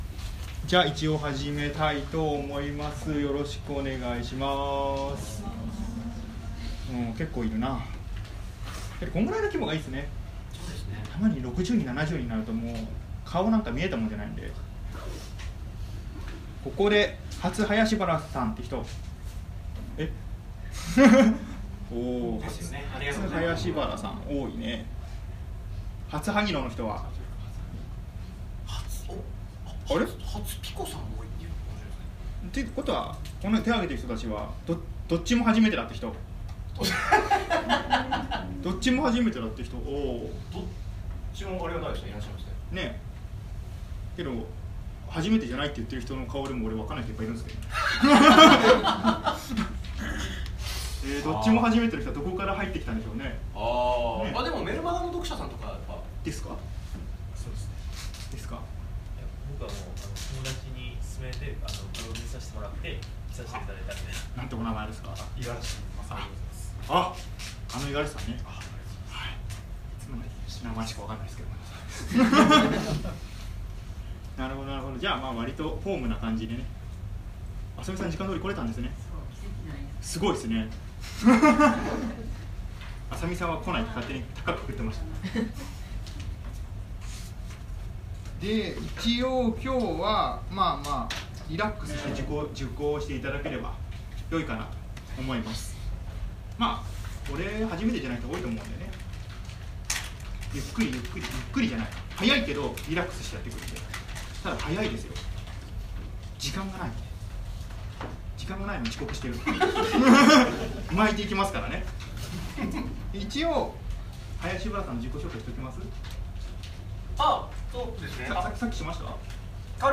時代＆人間のテイスティングセミナー-Part.1.m4a